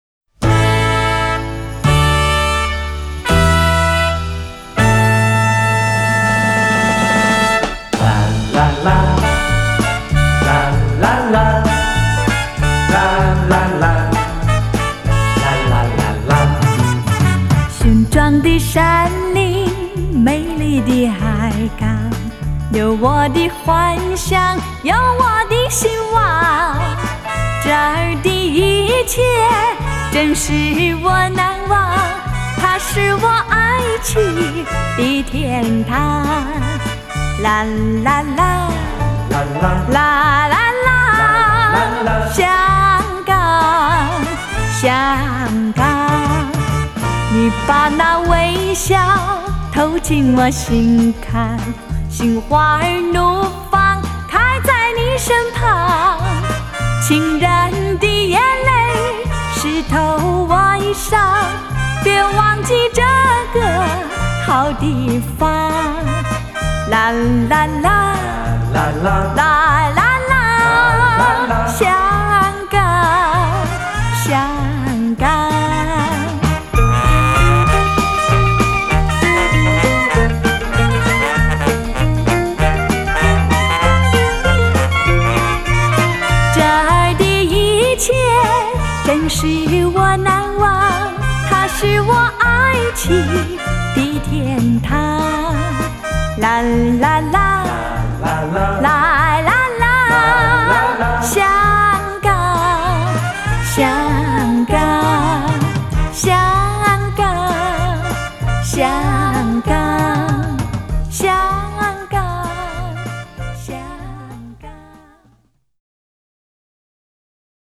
黑胶唱片，原音回放，珍藏绝版经典！